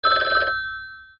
Звонок мобильного